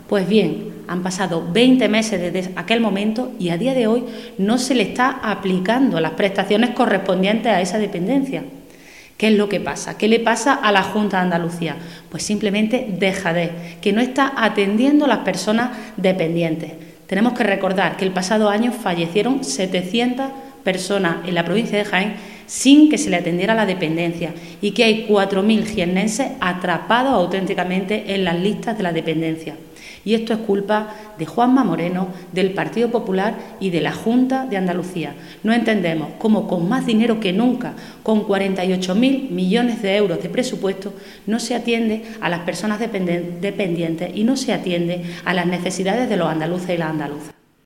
Cortes de sonido z Mercedes Gámez